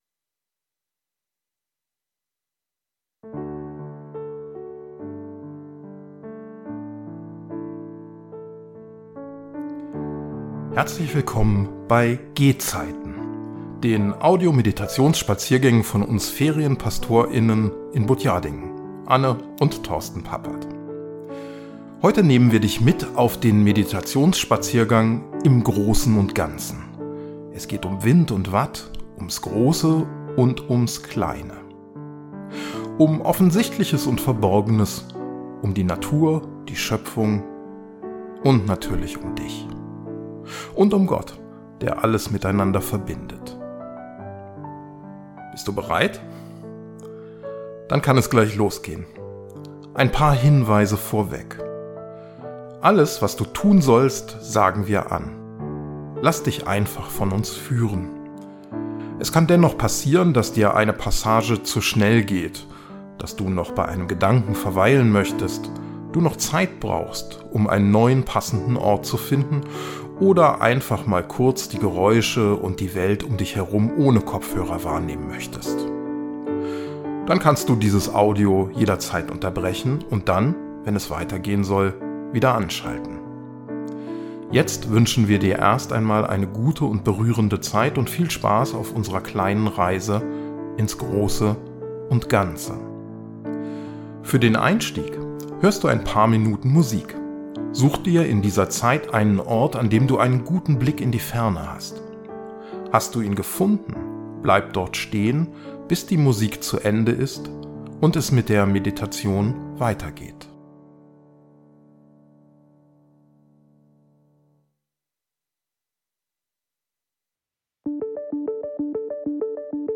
Der Spaziergang geschieht selbstbestimmt mit einer Anleitung in einer Audiodatei.